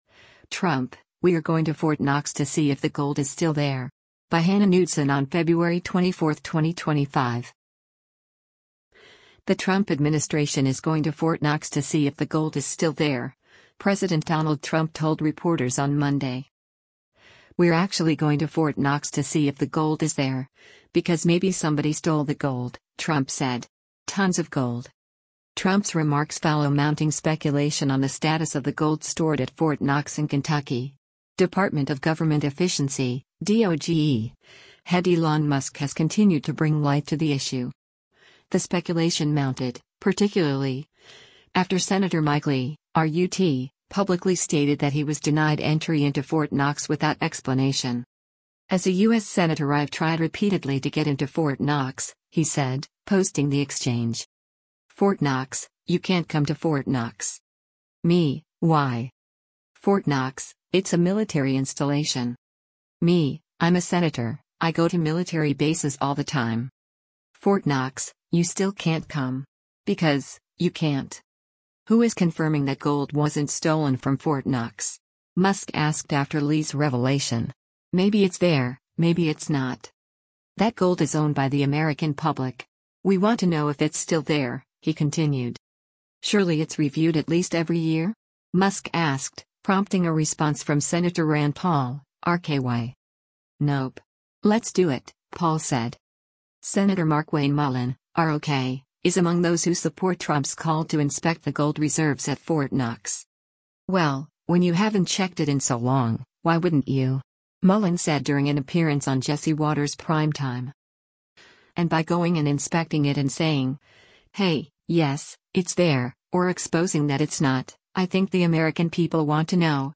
The Trump administration is going to Fort Knox to see if the gold is still there, President Donald Trump told reporters on Monday.